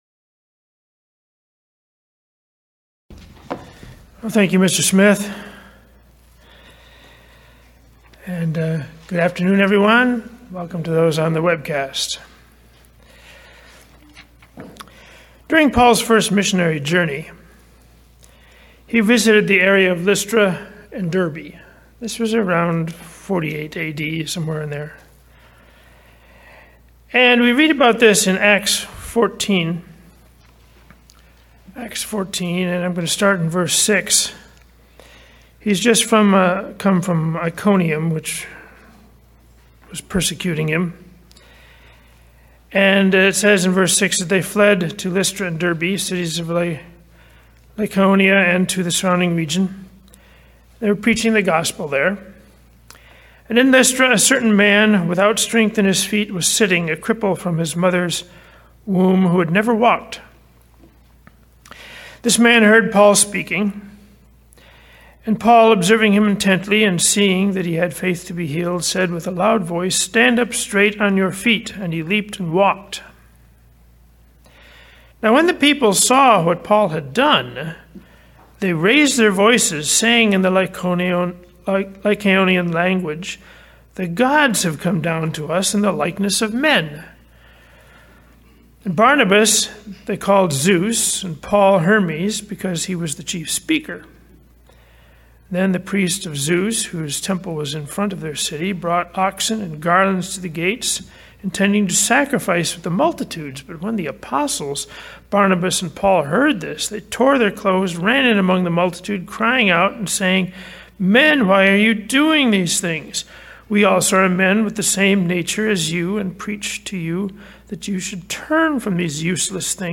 Sermons
Given in Las Vegas, NV Redlands, CA San Diego, CA